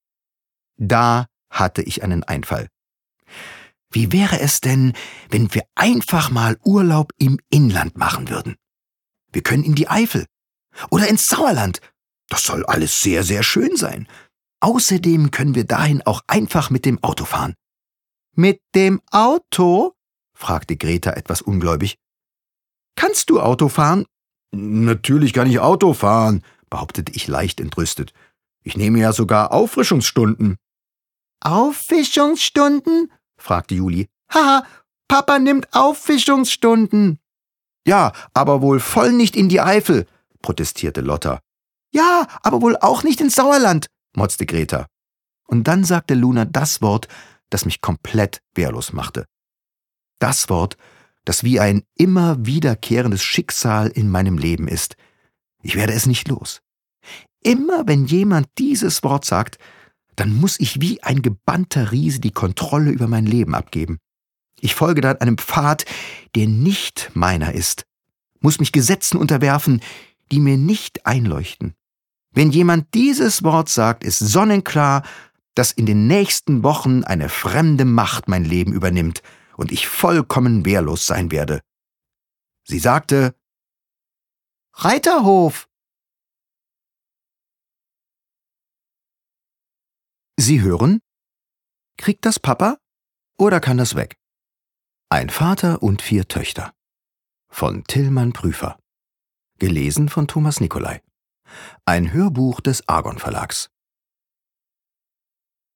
Thomas Nicolai (Sprecher)
Ungekürzte Lesung